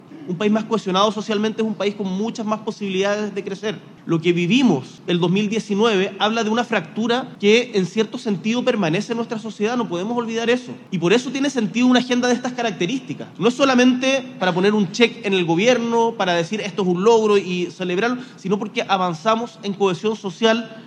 Esas palabras fueron parte del discurso del presidente Gabriel Boric en el acto del día del Trabajador, el que se llevó a cabo ayer, donde, además, reconoció los avances que se han logrado en materia laboral bajo su administración.